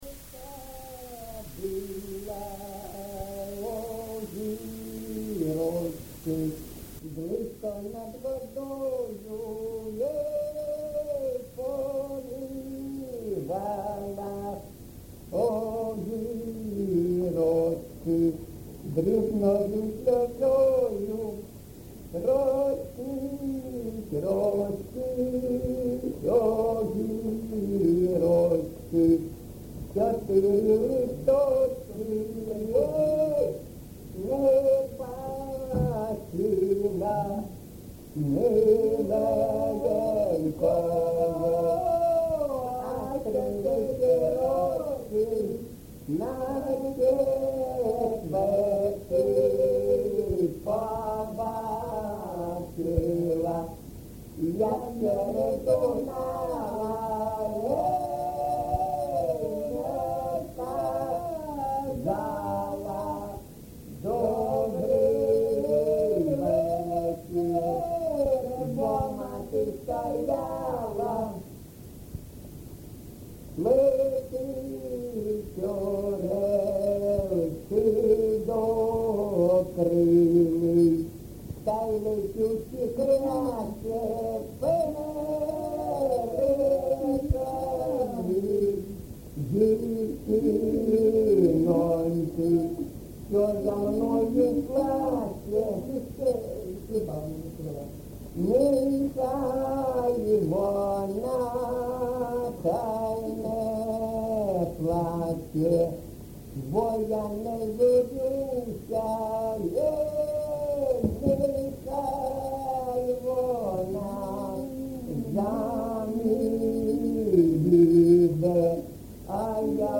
ЖанрПісні з особистого та родинного життя
Місце записум. Часів Яр, Артемівський (Бахмутський) район, Донецька обл., Україна, Слобожанщина